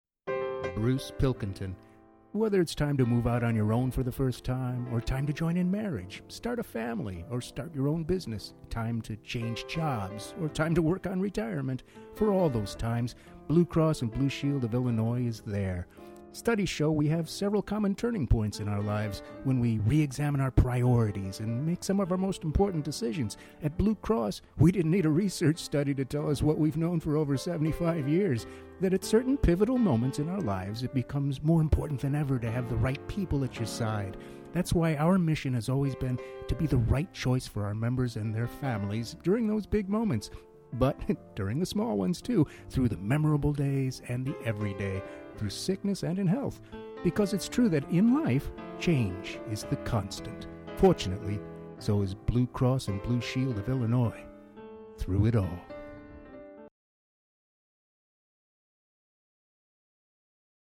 INSTINCTIVE CHOICES CHARACTER VOICES commercials,industrials: versatile, here to please the client! From real-person read to zany character voice
englisch (us)
Sprechprobe: Werbung (Muttersprache):
VERY directable, flexible Voice actor who can change directions, attitudes, approaches, etc. on a dime in order to fit YOUR needs! Mid-range, can do serious PSA, warm real-person, or zany character!